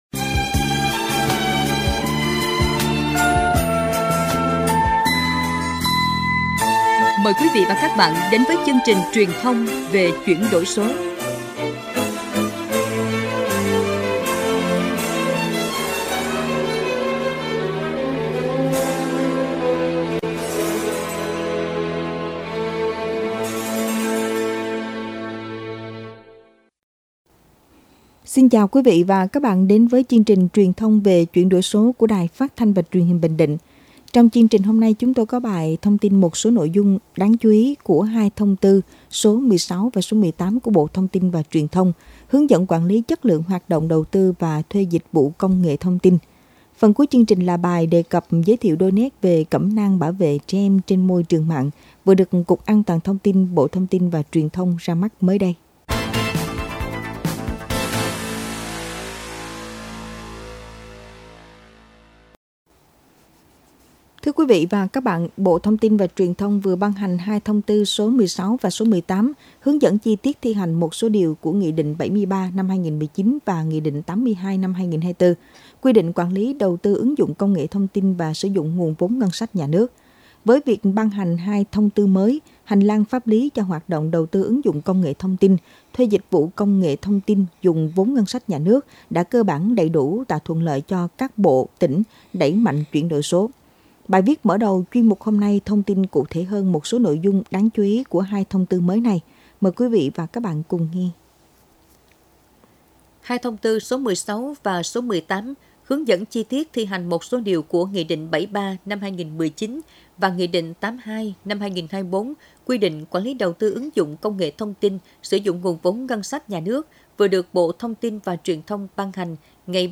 Thời Sự